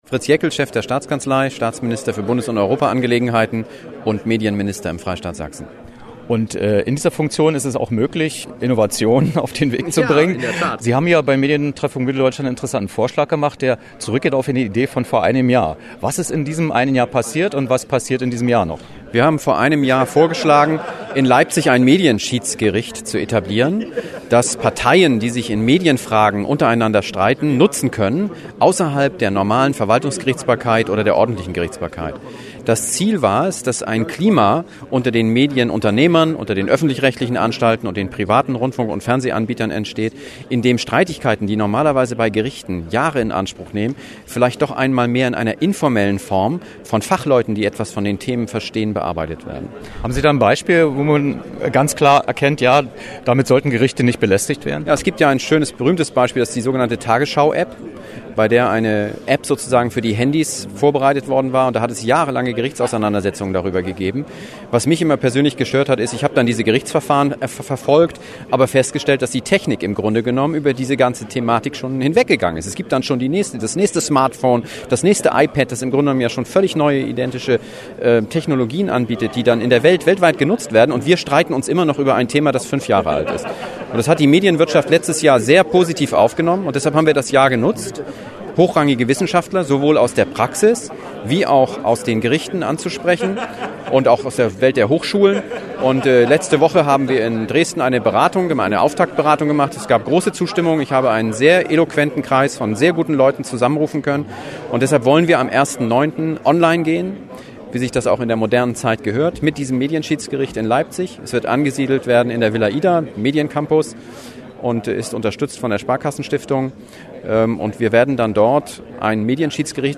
Wer: Staatsminister Dr. Fritz Jaeckel, Chef der Staatskanzlei des Freistaates Sachsen
Was: Interview über das neue Medien-Schiedsgericht am Rande des Medientreffpunkts Mitteldeutschland
Wo: Leipzig, Media City, Studio 3